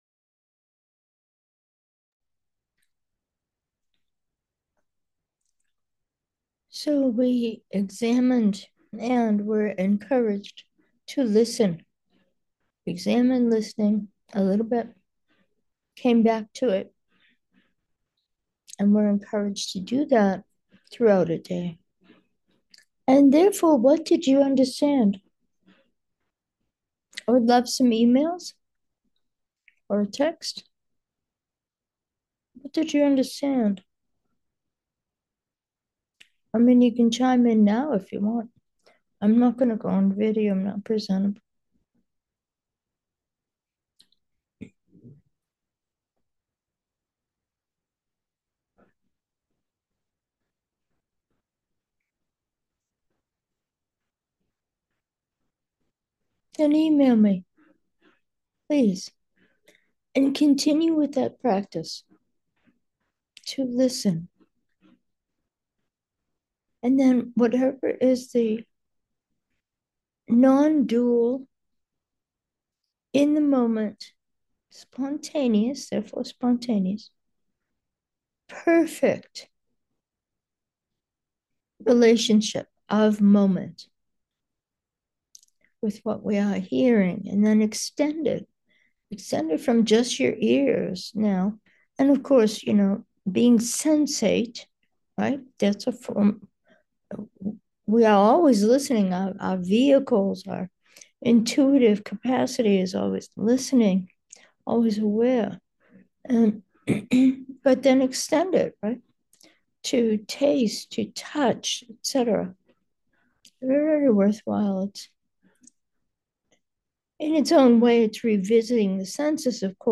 Meditation: changing perceptions